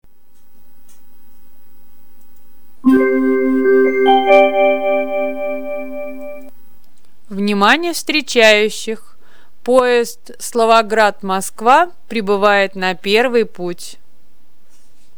Аудиозапись голоса диктора: "На первый путь пребывает поезд Словоград-Москва". (